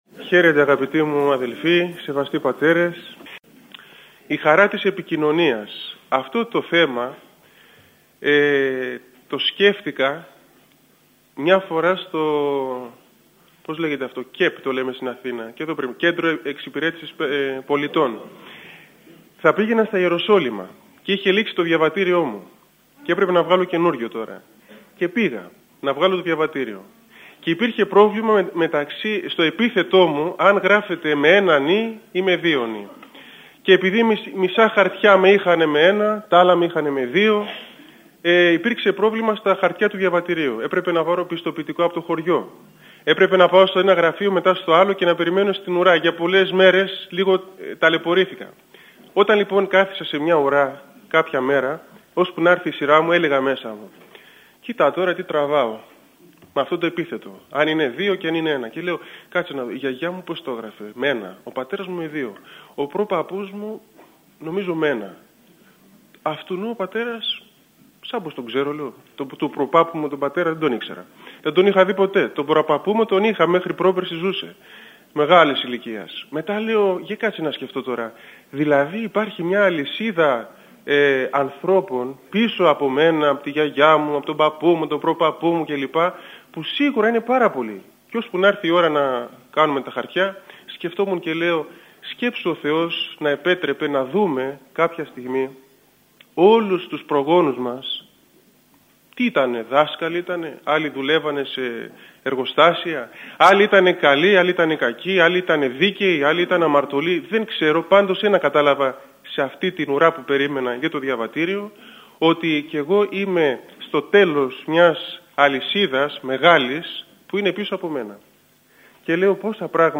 Η ομιλία αυτή πραγματοποιήθηκε στην αίθουσα της Χριστιανικής εστίας Πατρών.